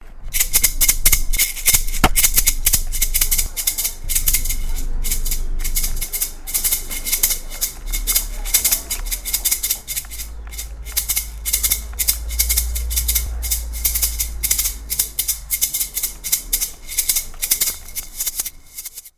Famille : percussions
Descriptif : c’est un instrument avec un manche en bois et une sorte de sphère au bout. Cette dernière est remplie de graine, de sable ou autre afin de produire le son.
Maracas